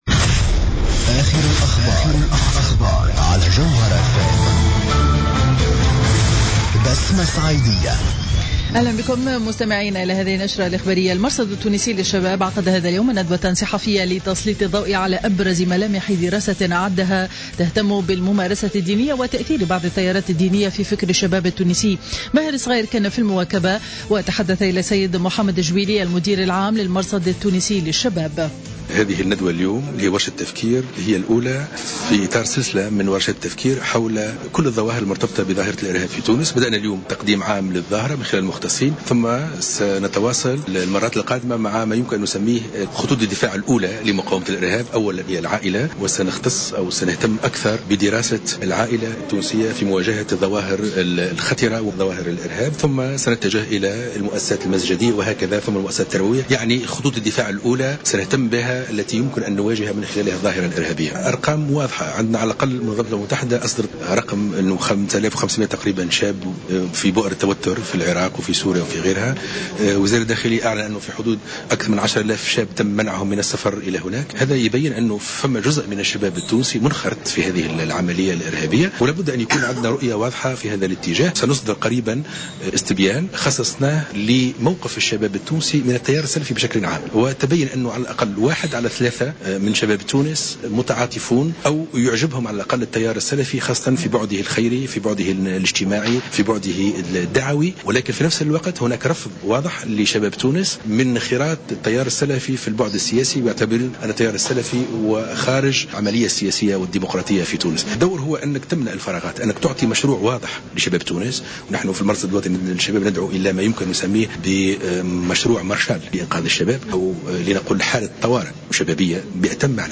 نشرة أخبار منتصف النهار ليوم الثلاثاء 14 جويلية 2015